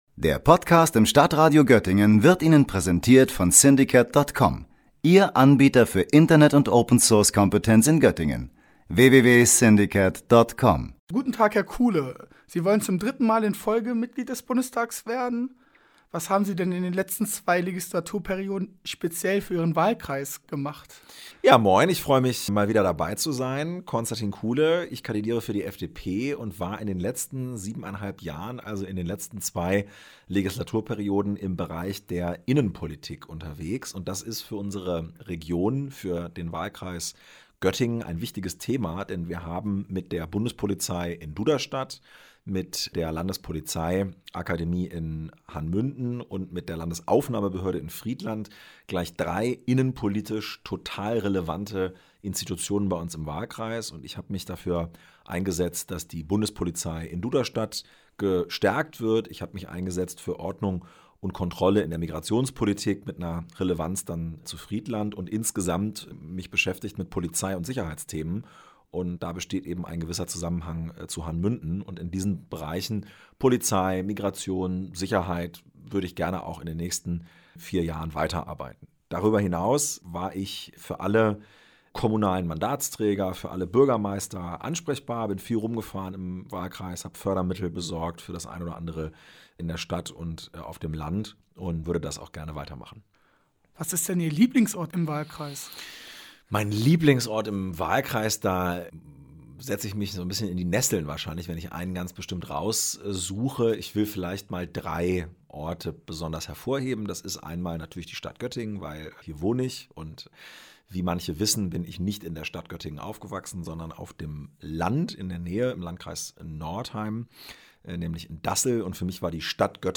Beiträge > „Die FDP ist die Partei der Meinungsfreiheit“ – Konstantin Kuhle im Interview - StadtRadio Göttingen